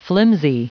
Prononciation du mot flimsy en anglais (fichier audio)
Prononciation du mot : flimsy